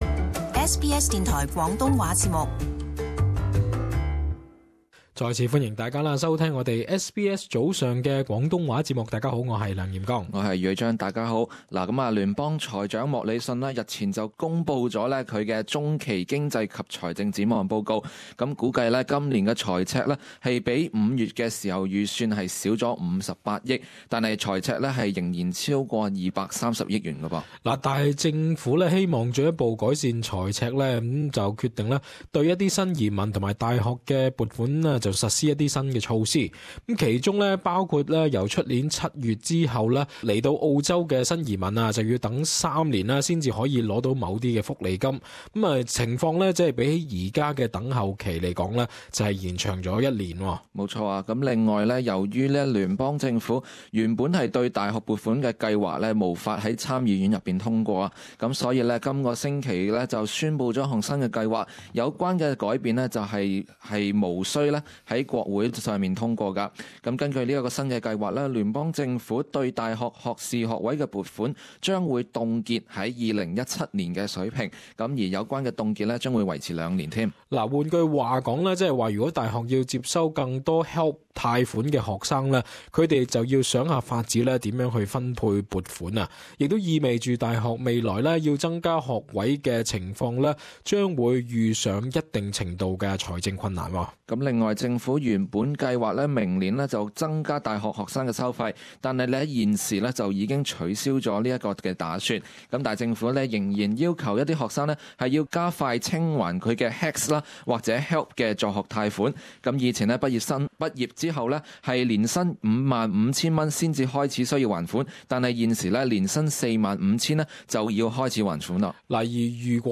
亦邀請聽眾朋友發表意見。